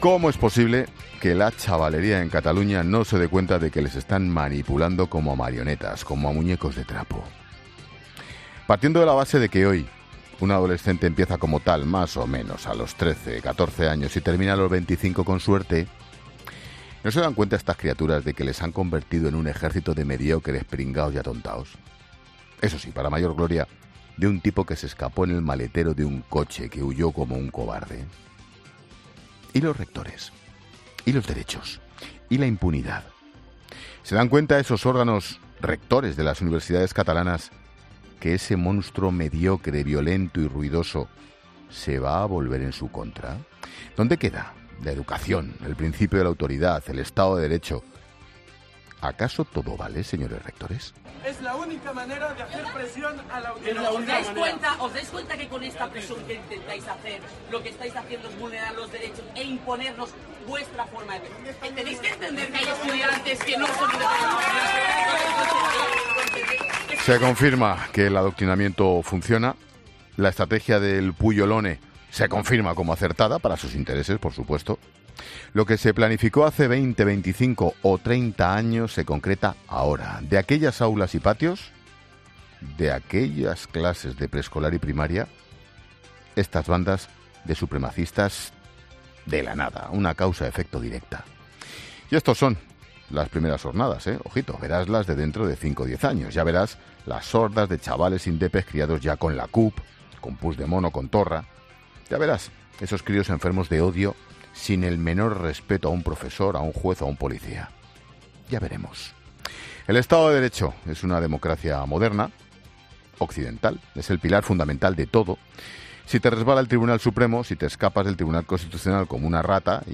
Monólogo de Expósito
El presentador de La Linterna analiza la manipulación en los piquetes independentistas en las universidades catalanas